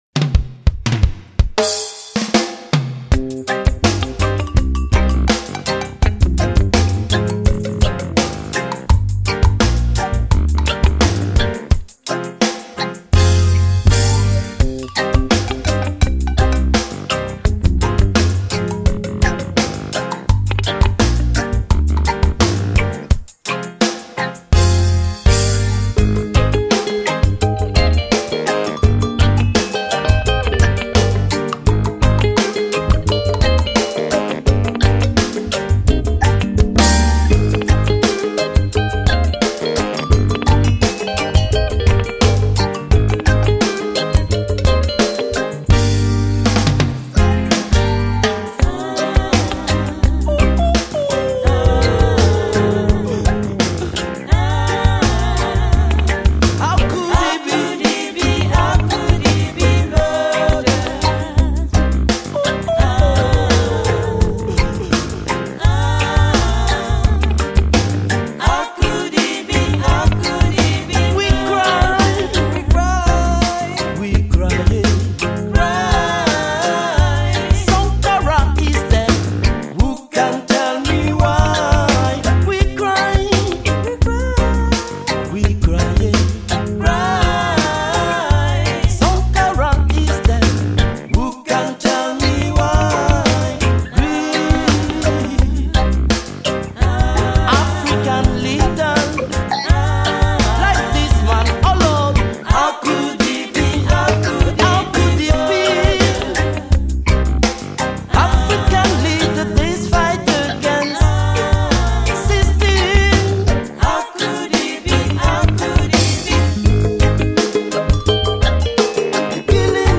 guitare
trombone
basse
batterie
trompette
chant lead / guitare
– du reggae roots avant tout, mais aussi du rock et du dub